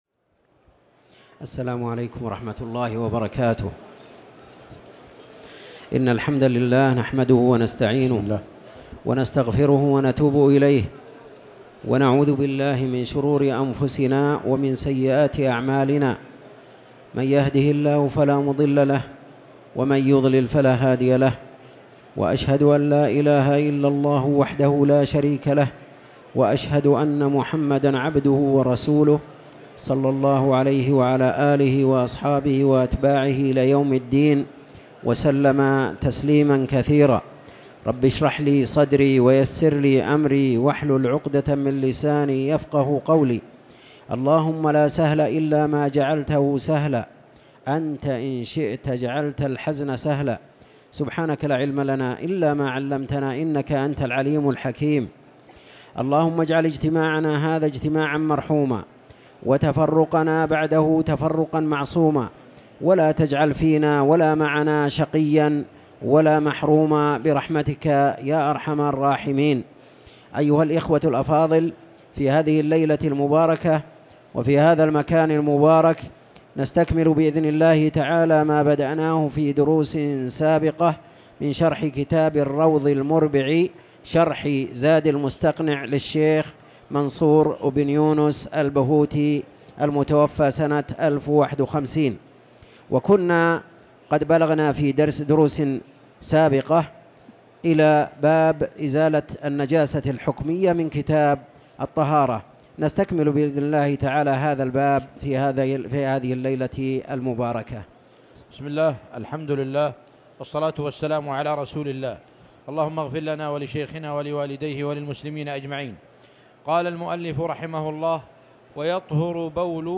تاريخ النشر ٤ رجب ١٤٤٠ هـ المكان: المسجد الحرام الشيخ